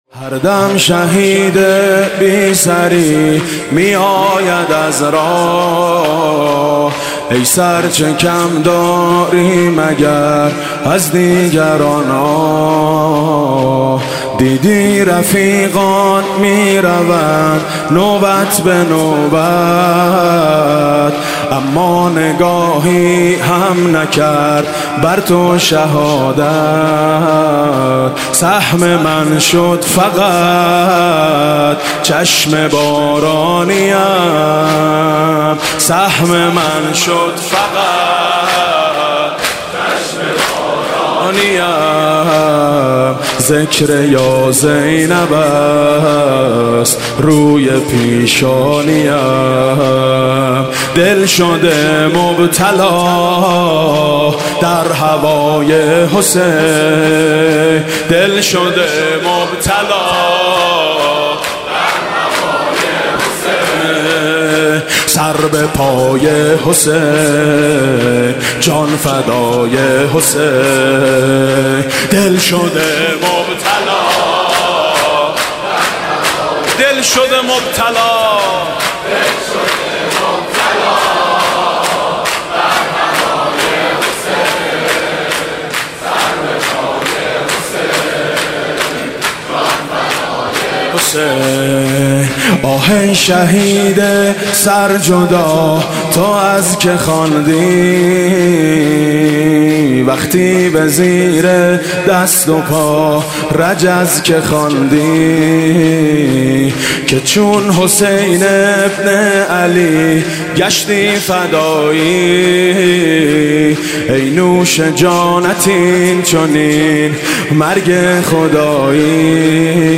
مداحی فارسی_عربی میثم مطیعی به مناسبت بازگشت پیکر شهید حججی + متن شعر
در شب پنجم محرم اجرا شد
شب گذشته میثم مطیعی در هیئت میثاق با شهداء که در مسجد دانشگاه امام صادق(ع) برگزار شد، به مدیحه سرایی درباره بازگشت پیکر مطهر این شهید پرداخت که در ادامه صوت آن منتشر می شود: